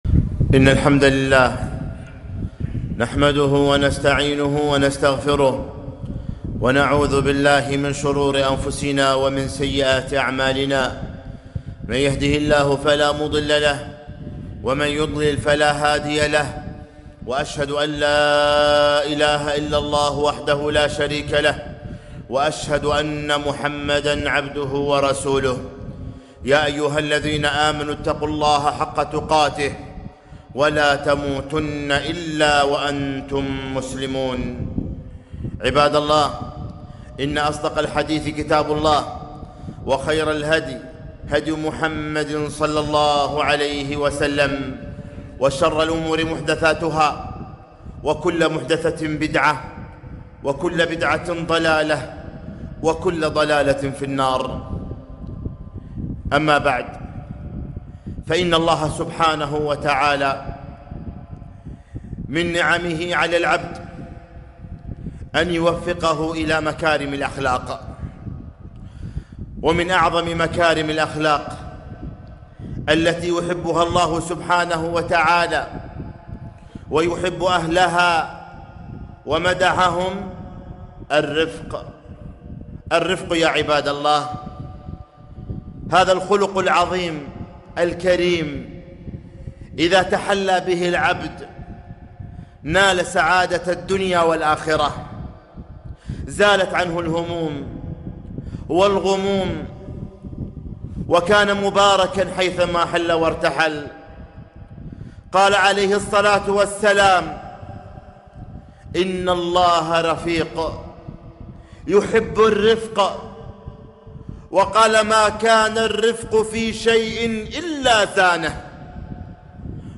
خطبة - ما كان الرفق في شيء إلا زانه